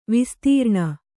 ♪ vistīrṇa